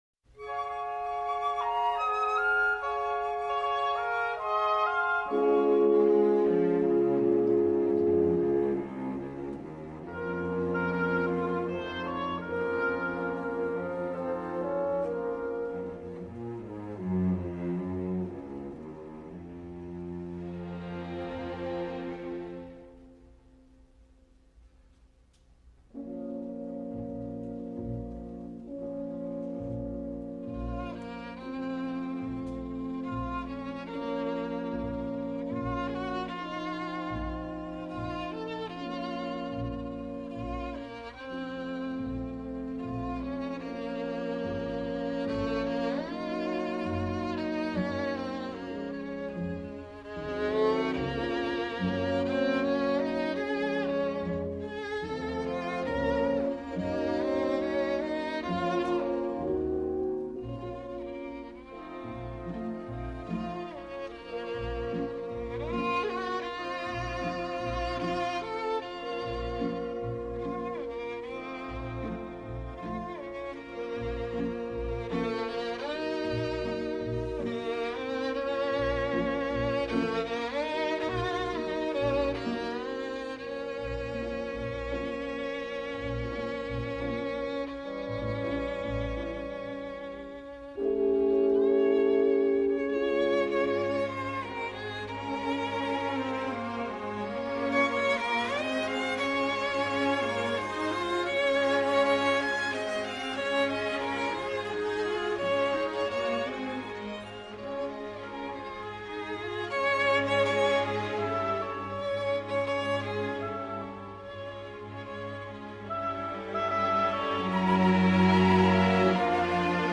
“Sérénade mélancolique”, Op. 26 by Tchaikovsky, performed by Jascha Heifetz:
jascha-heifetz-plays-tchaikovsky-serenade-melancolique.mp3